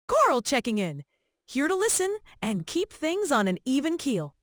NavTalk 提供多种高质量的语音合成风格，您可以通过 voice 参数自由选择数字人音色：
专业清晰播音腔